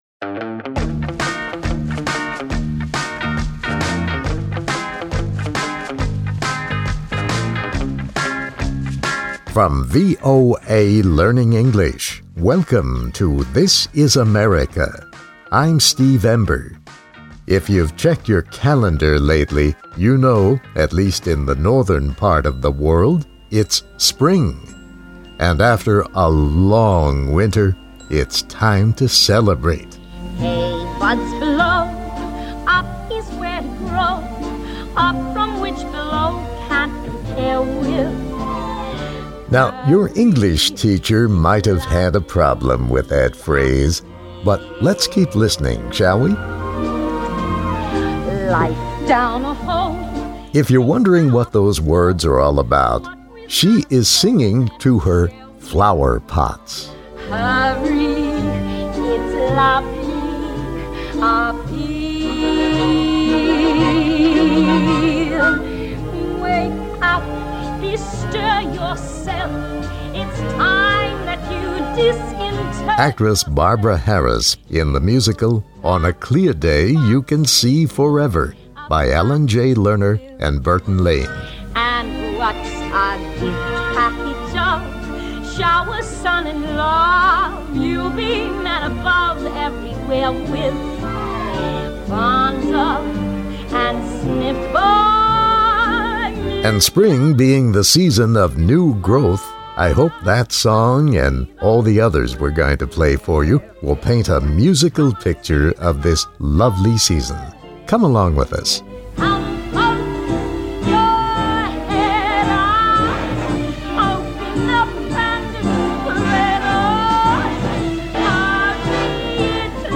Songs about Spring express both joy and sadness. Come along as we sample the many moods of Spring, from Broadway, Hollywood, pop, country, even a cowboy tune | This Is America